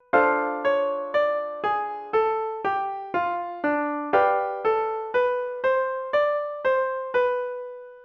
Not too thrilling, but it might serve as a skeleton to build on, perhaps like this:
The added non-harmonic tones add a bit more life.